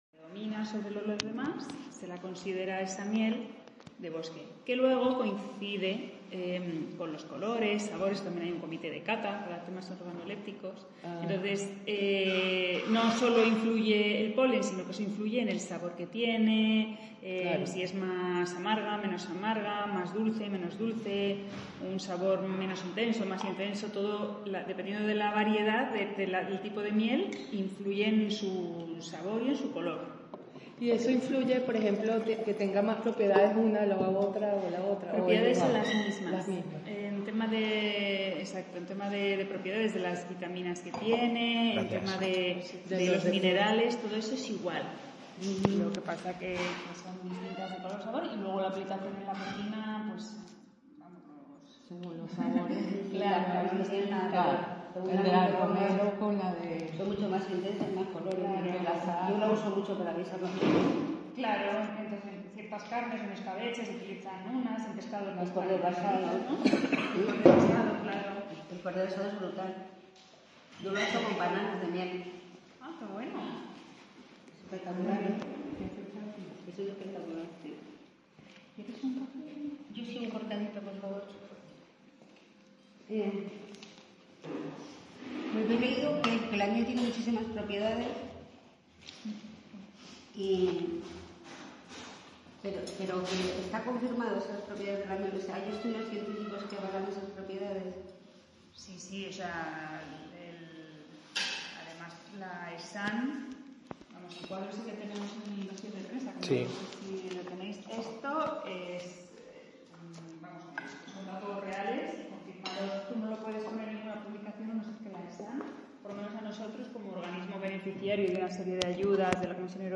Entrevista acerca de la miel, una delicia que nos acompaña desde tiempos inmemoriales